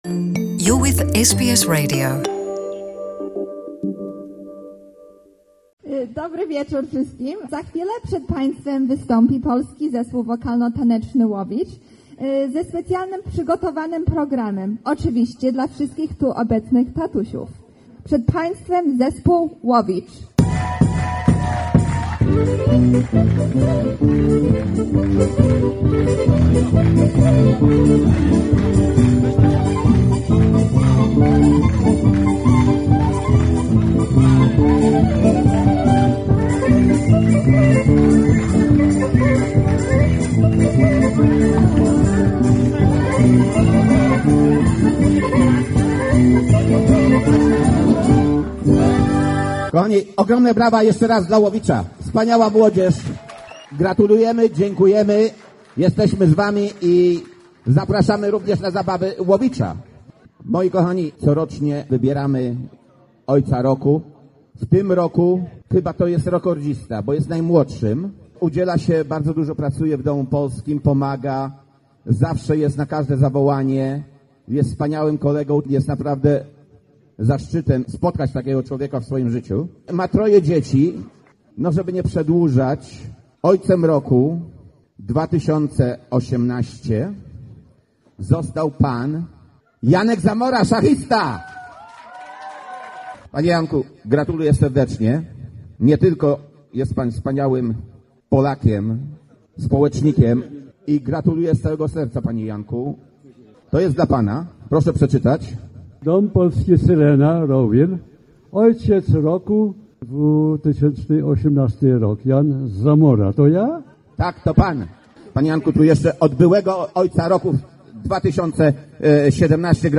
The report from the ' Father's Day event in Polish House ' Syrena' in Melbourne